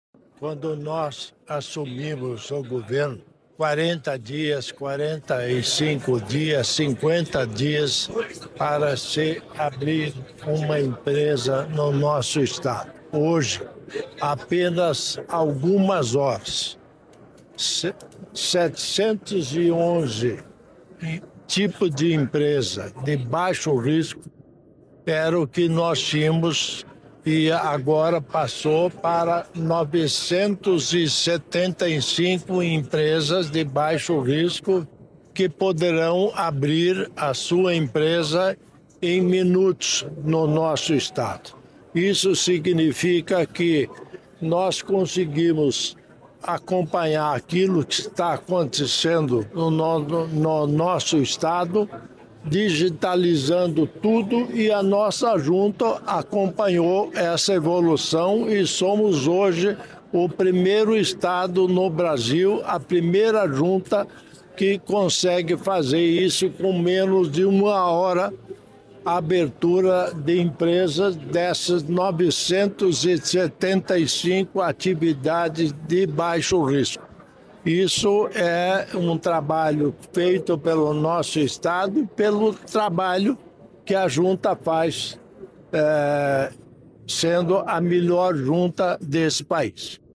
Sonora do governador em exercício Darci Piana sobre ferramenta de georreferenciamento para acelerar abertura de empresas